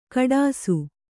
♪ kaḍāsu